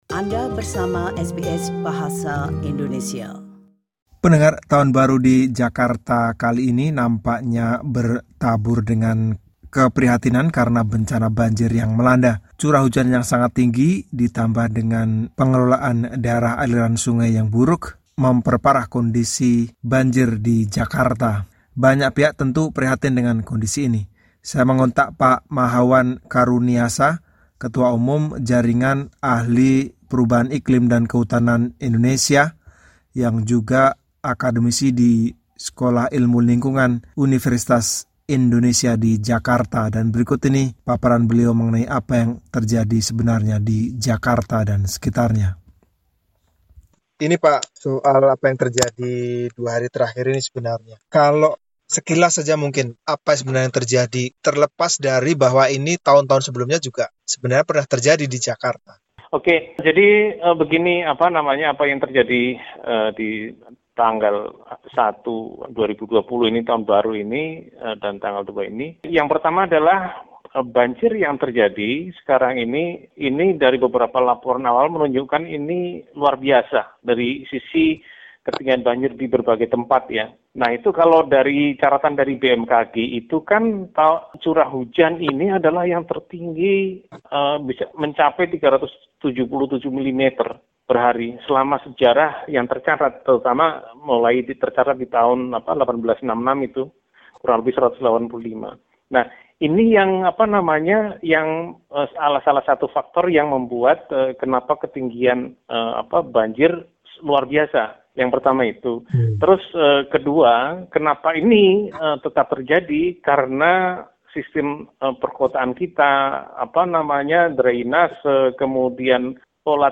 Berikut wawancara